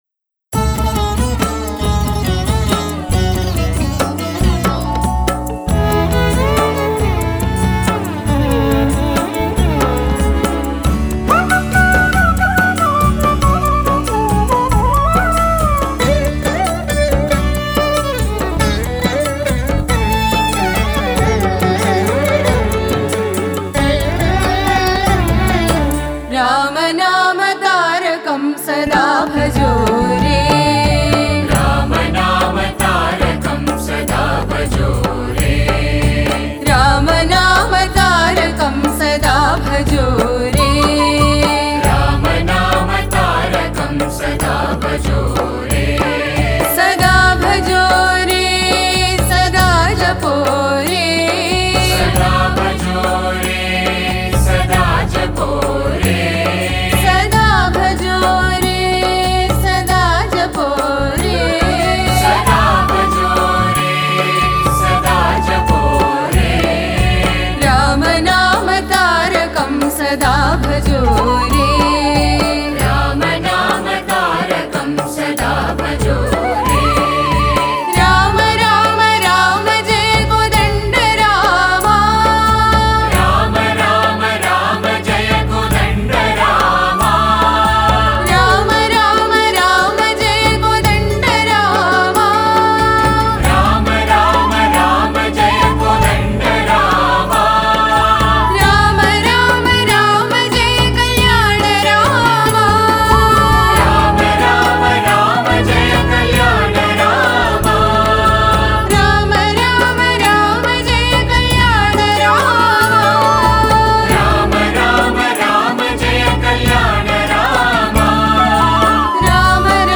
Author adminPosted on Categories Rama Bhajans